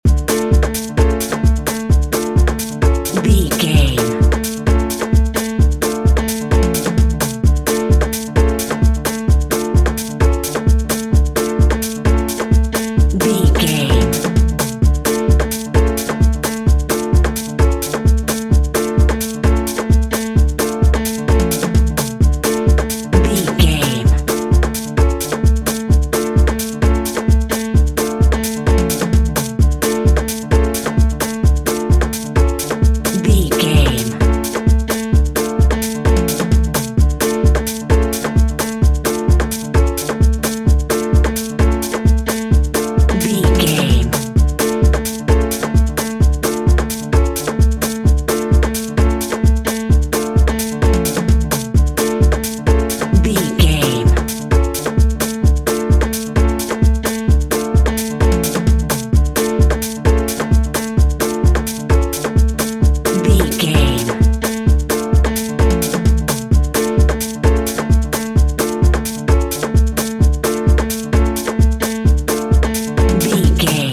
Aeolian/Minor